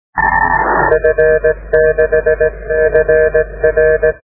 Les fichiers à télécharger sont compressés au format MP3 à 1ko/sec, ce qui explique la très médiocre qualité du son.
Repassé à vitesse normale le message devient compréhensible :
trafic par meteor scatter